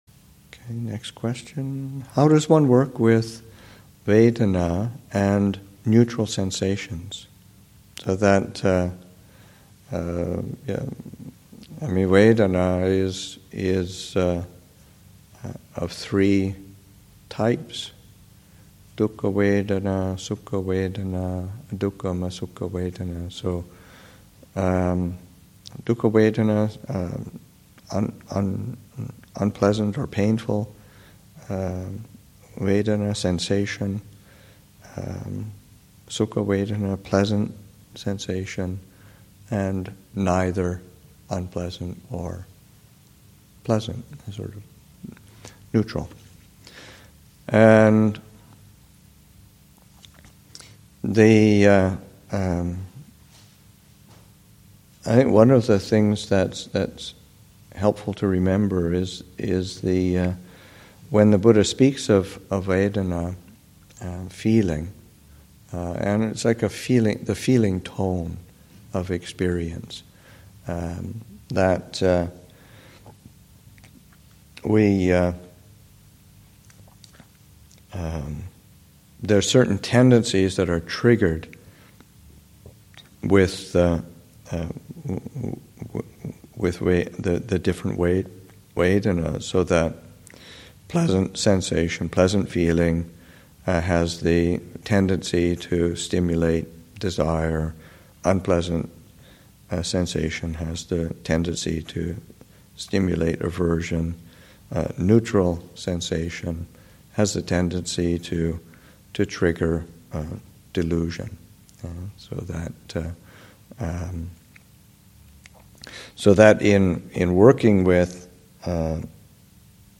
2014 Thanksgiving Monastic Retreat, Session 1 – Nov. 22, 2014